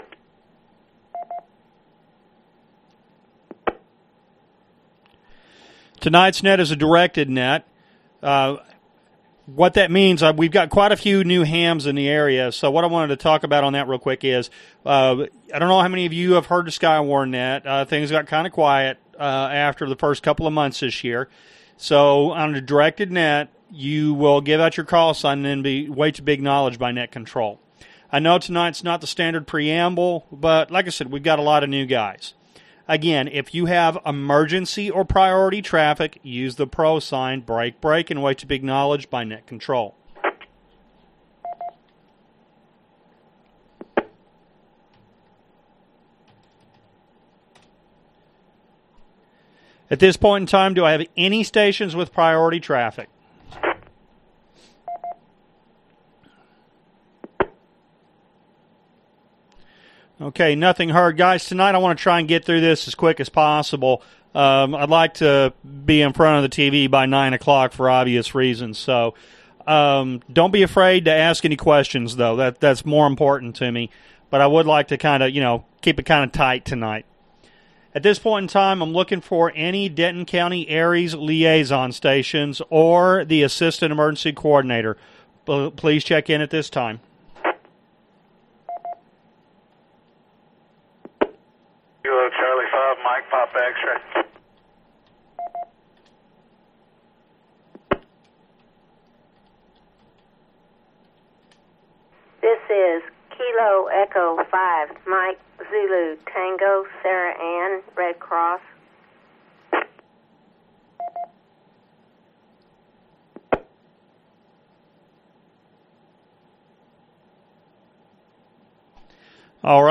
ARES Training Net Recordings
July 2016 ARES Training Net Audio in mp3 format.